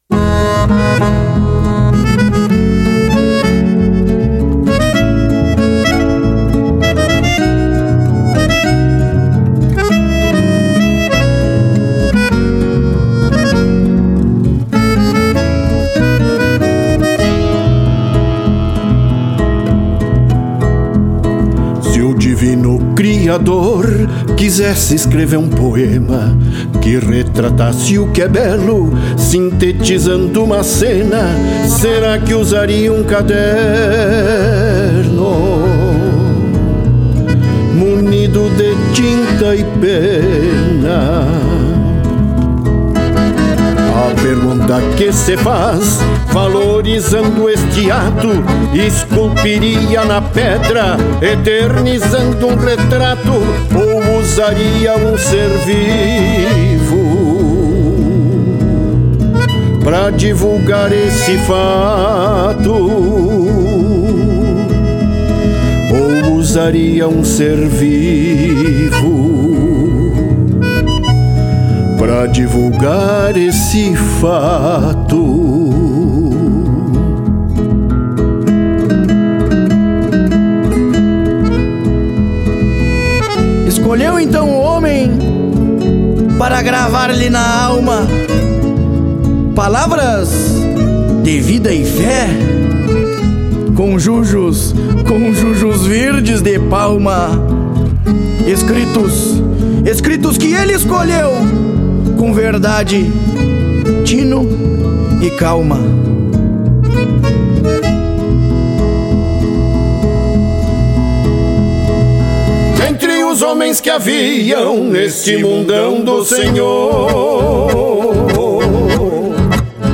Violão Solo
Acordeon
POESIA DA ALMA (1º Lugar) – 8ª MONTA DA CANÇÃO